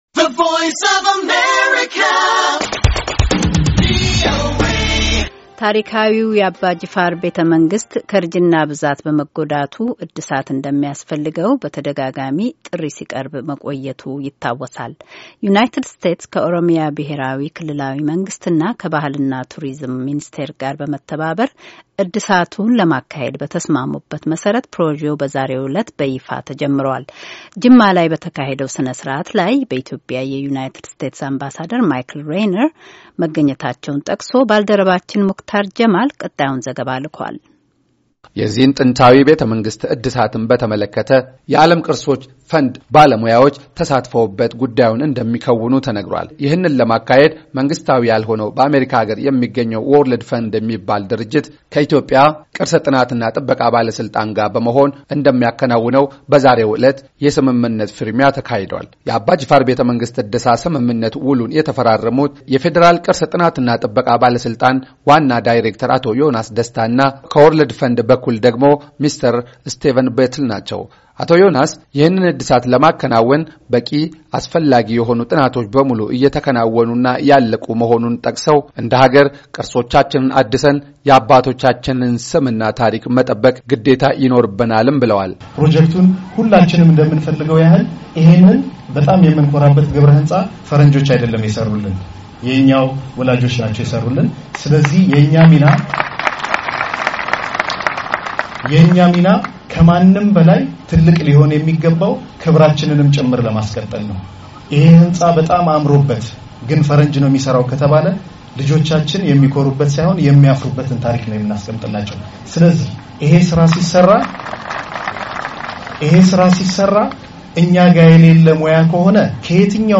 ተከታዩን ዘገባ ልኳል።።